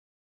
zombi_bomb_idle_10.wav